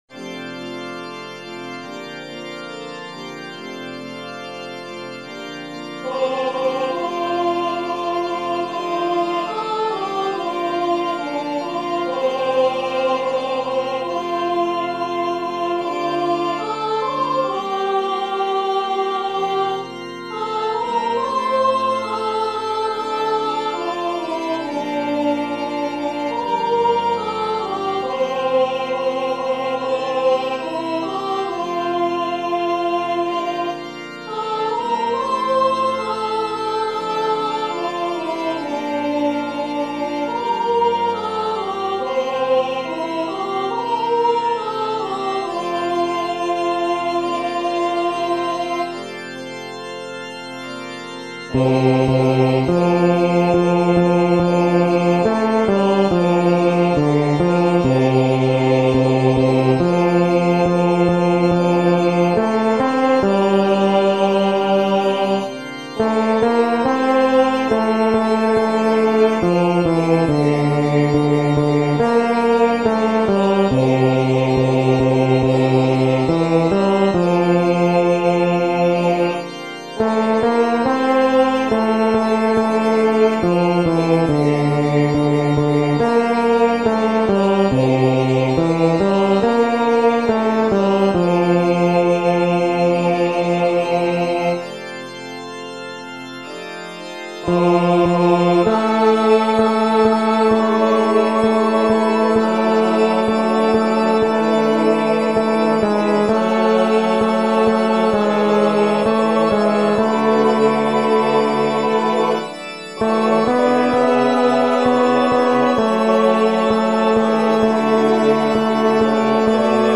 テノール1（フレットレスバス音）